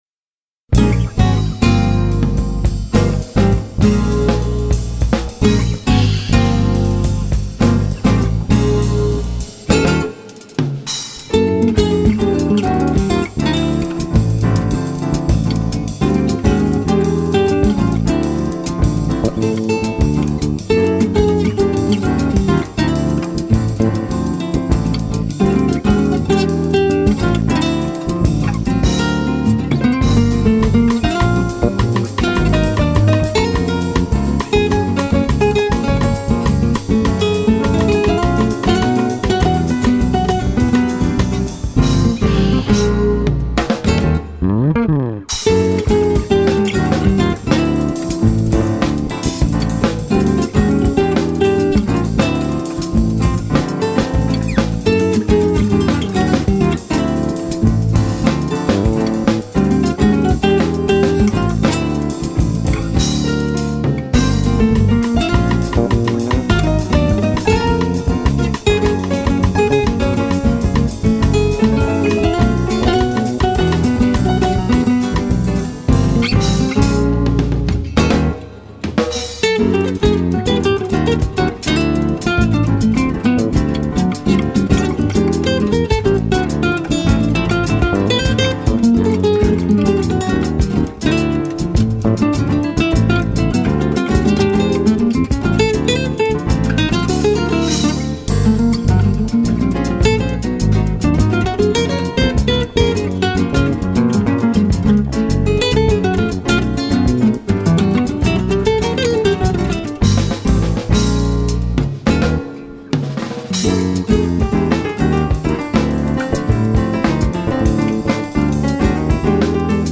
1362   03:27:00   Faixa:     Jazz
Violao Acústico 6
Bateria
Baixo Elétrico 6
Teclados